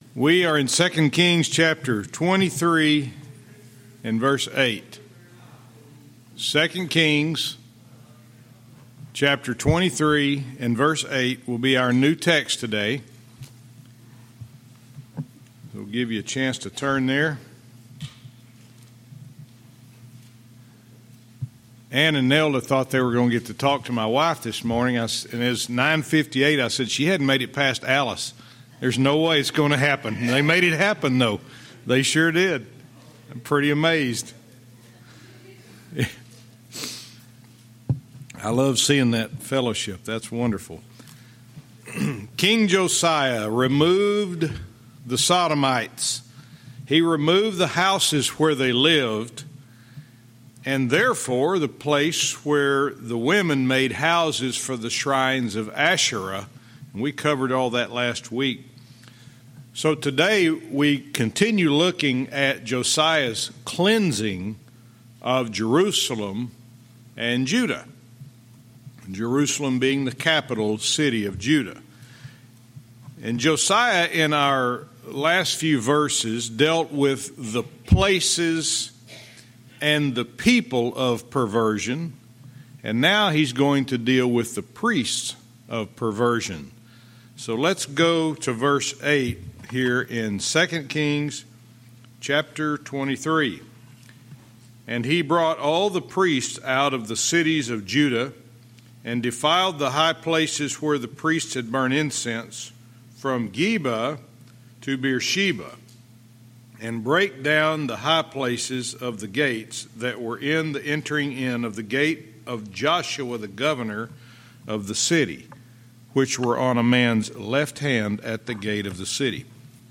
Verse by verse teaching - 2 Kings 23:8-9